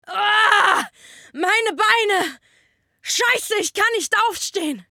Synchron – RPG – Soldier